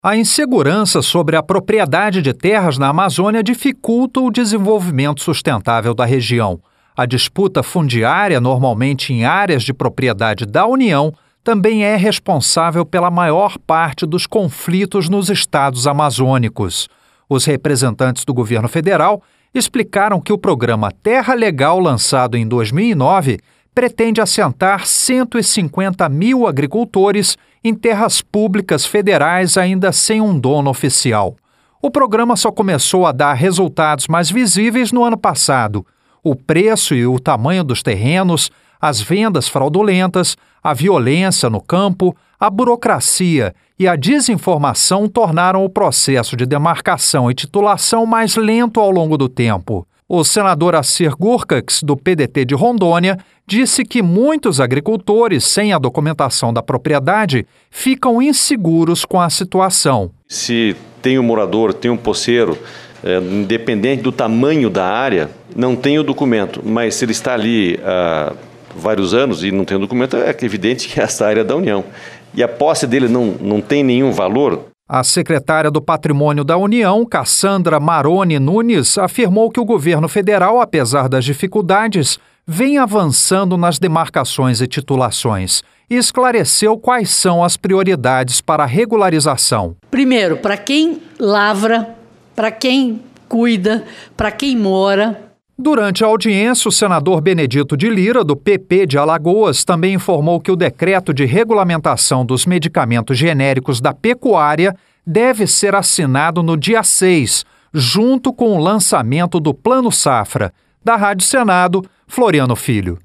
LOC: A INFORMAÇÃO FOI PRESTADA PELOS REPRESENTANTES DO INCRA E DO MINISTÉRIO DO DESENVOLVIMENTO AGRÁRIO QUE PARTICIPARAM PELA MANHÃ DE UMA AUDIÊNCIA PÚBLICA NO SENADO.